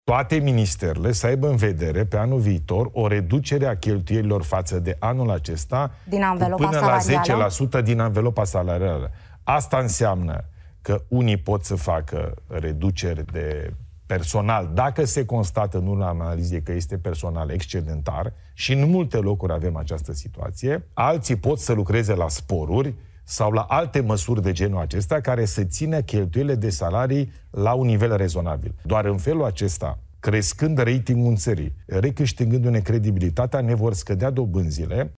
Este declarația premierului Ilie Bolojan, într-un interviu pentru știrile ProTV, după ce ministrul Ionuț Moșteanu a spus că nu este de acord să se taie din bugetul Apărării. Ilie Bolojan spune că reducerile de cheltuieli sunt necesare pentru ca România să recâștige încrederea creditorilor, ceea ce înseamnă împrumuturi cu dobânzi mai mici pe viitor.